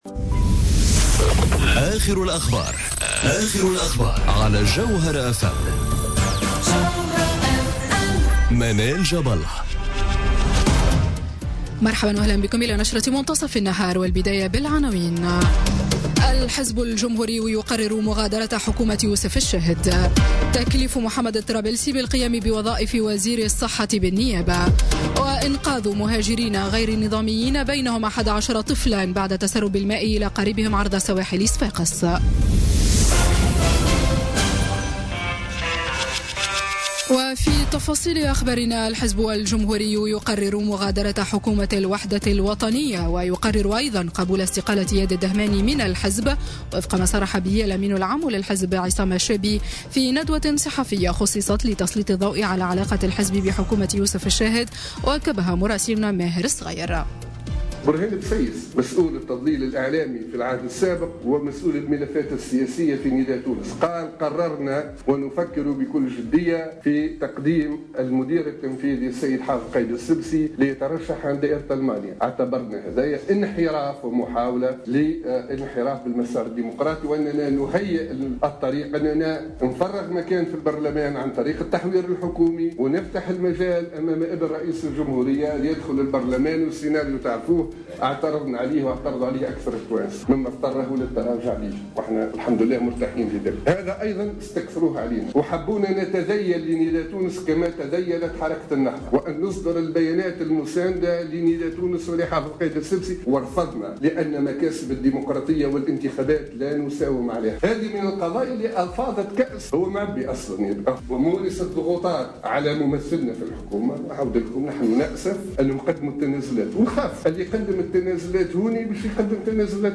نشرة أخبار منتصف النهار ليوم الإثنين 6 نوفمبر 2017